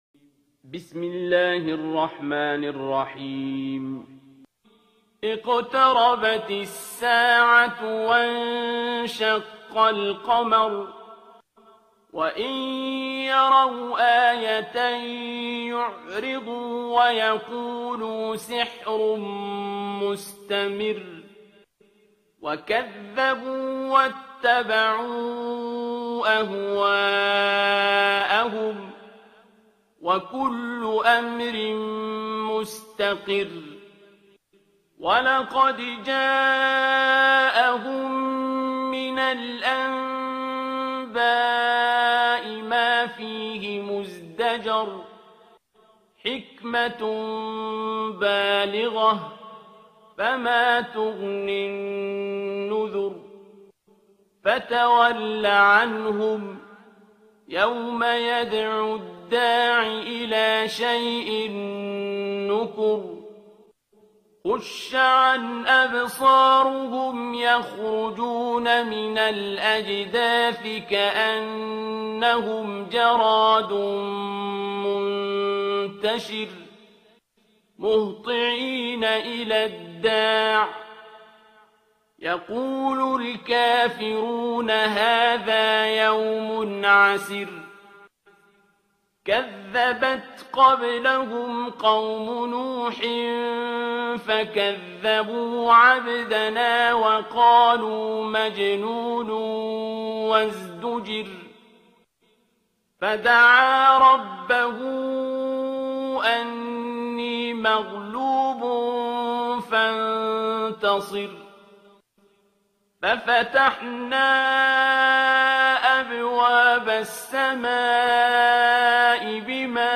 ترتیل سوره قمر با صدای عبدالباسط عبدالصمد
054-Abdul-Basit-Surah-Al-Qamar.mp3